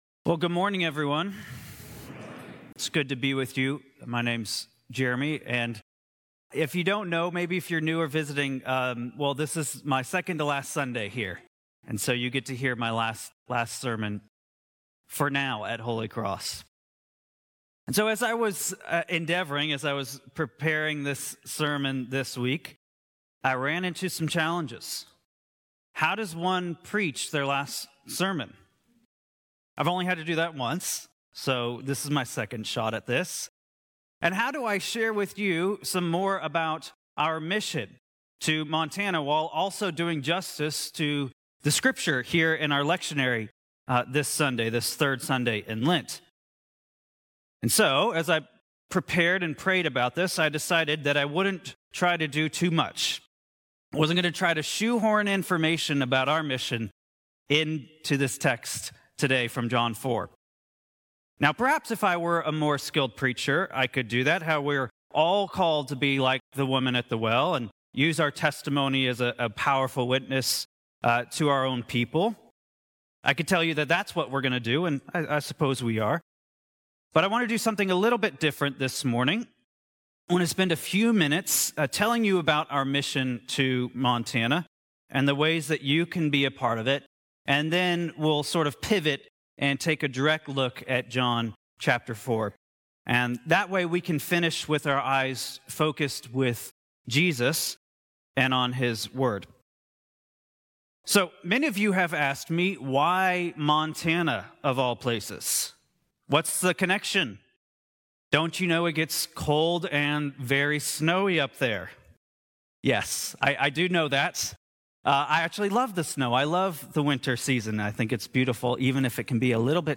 Sermons - Holy Cross Anglican Cathedral